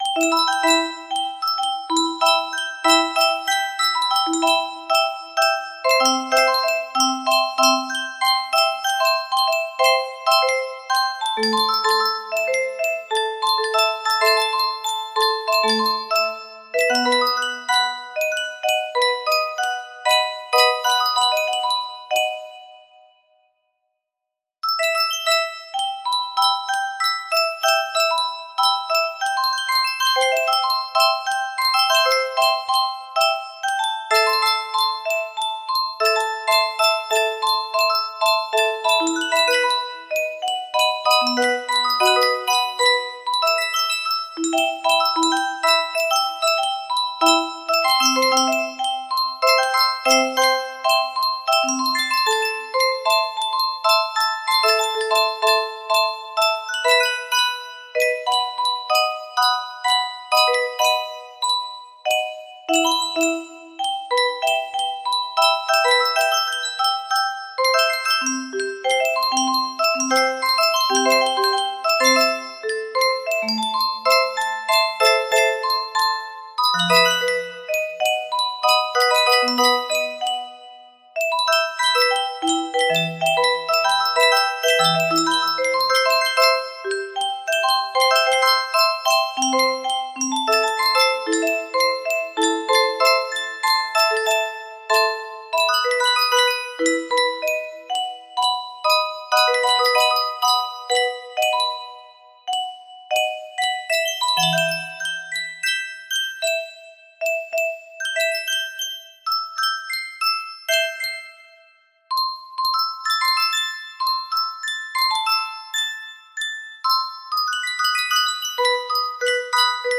Mystic Lullaby music box melody
Full range 60